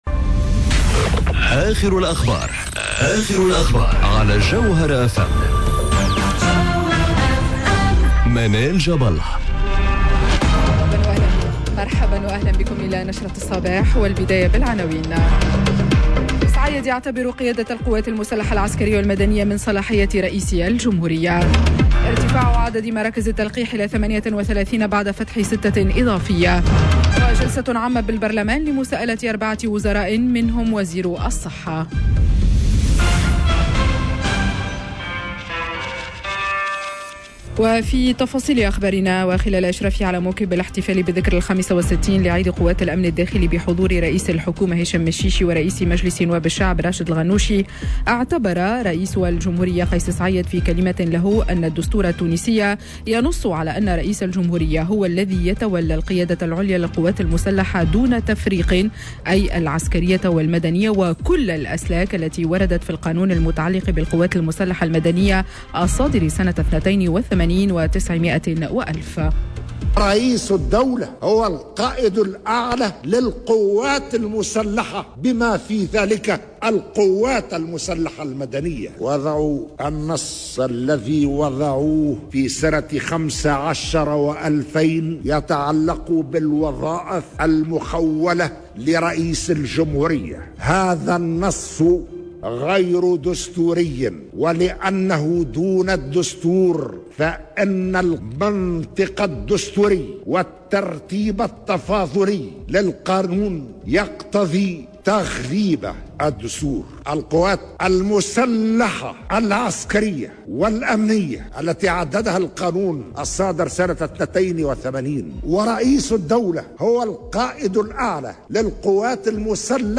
نشرة أخبار السابعة صباحا ليوم الإثنين 19 أفريل 2021